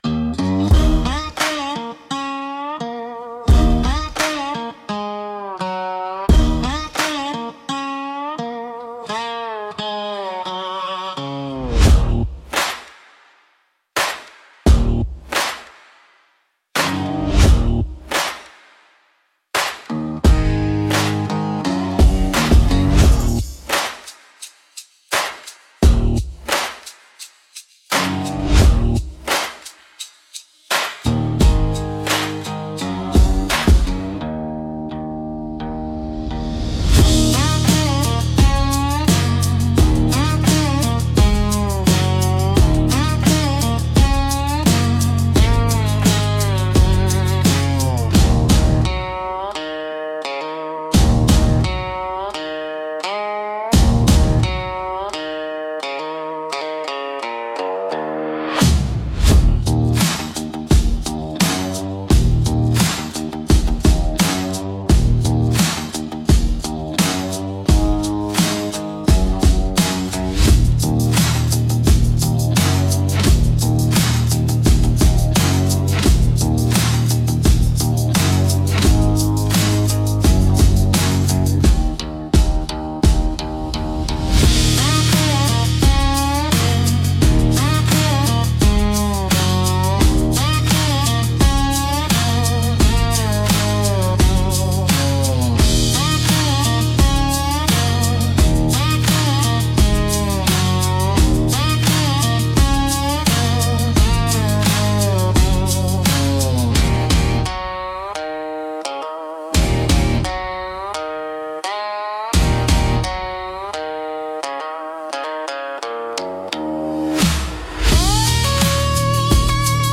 Swampy Dark Country